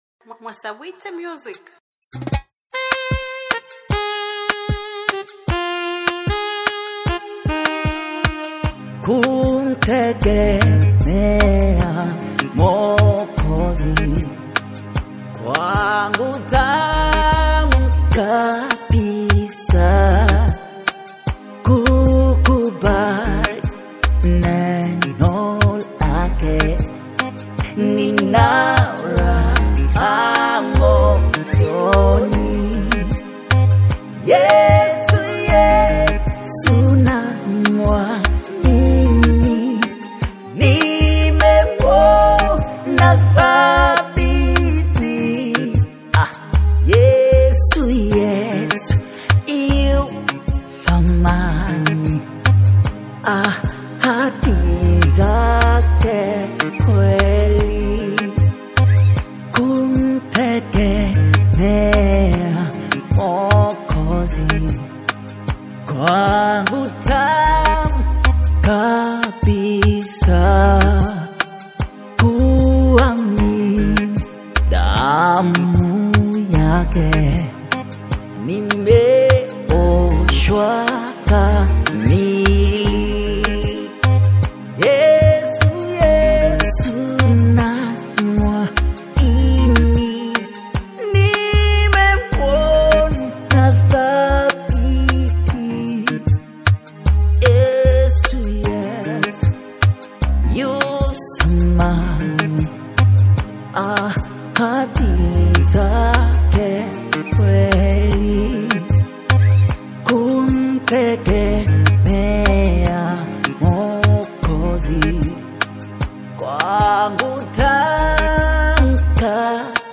Tanzanian gospel music